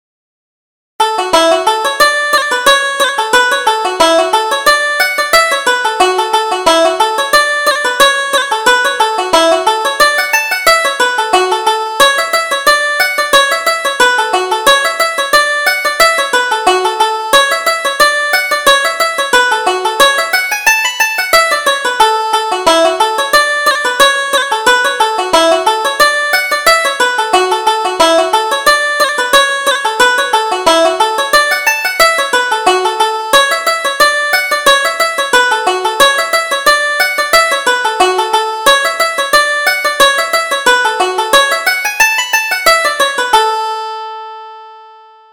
Reel: The Rose in the Garden